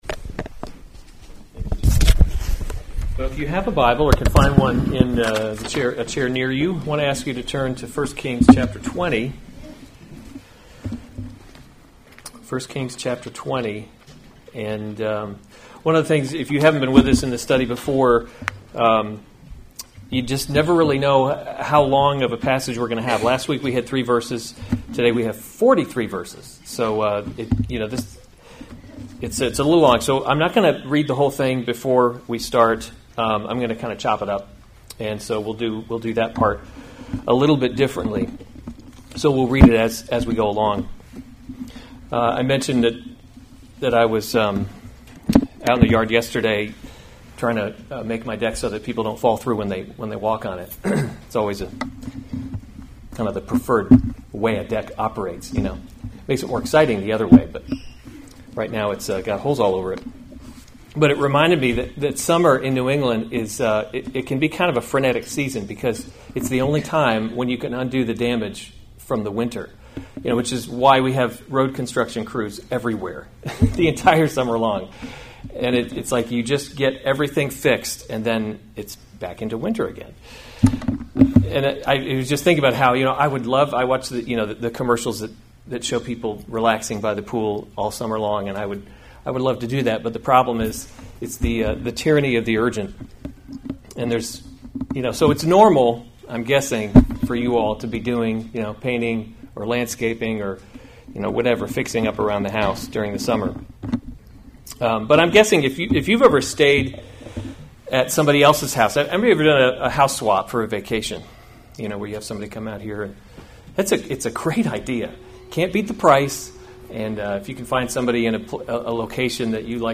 July 6, 2019 1 Kings – Leadership in a Broken World series Weekly Sunday Service Save/Download this sermon 1 Kings 20 Other sermons from 1 Kings Ahab’s Wars with Syria […]